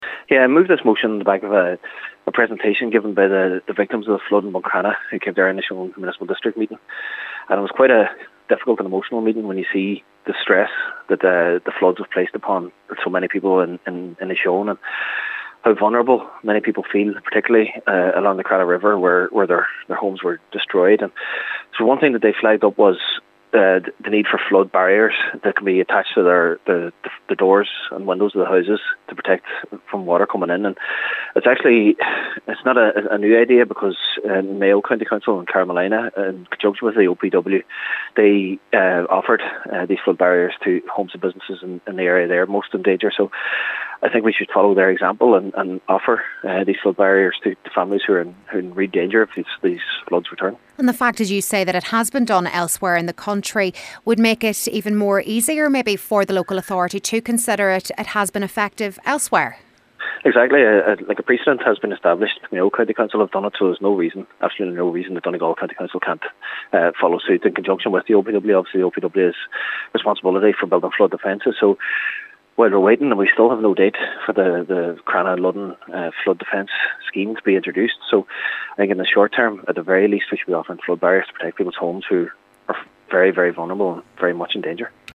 Cllr Jack Murray says the initiative has worked elsewhere, and these people need the tools to protect their properties: